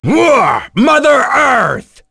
Phillop-Vox_Skill3_b.wav